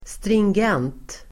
Ladda ner uttalet
Folkets service: stringent stringent adjektiv, stringent Uttal: [stringg'en:t] Böjningar: stringent, stringenta Synonymer: följdriktig, noggrann, strikt Definition: som följer en logisk linje, följdriktig (cogent)